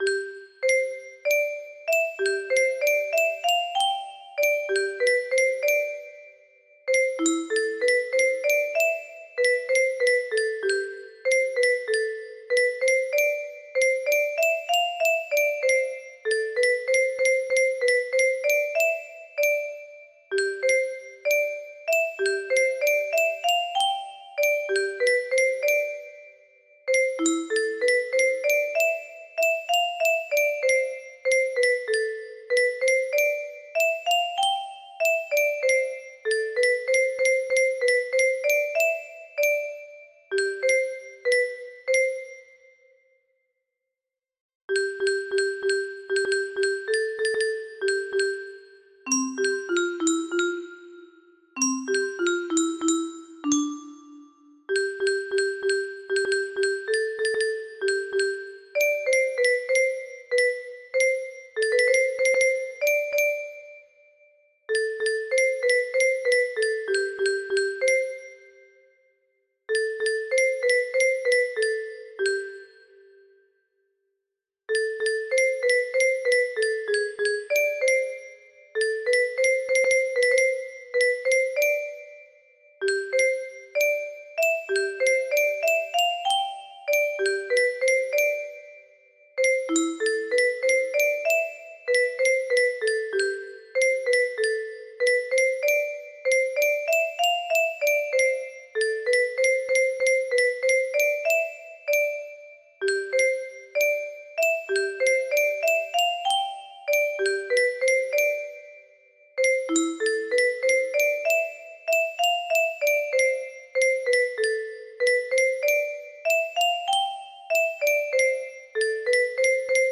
Unknown Artist - Untitled music box melody
It looks like this melody can be played offline on a 30 note paper strip music box!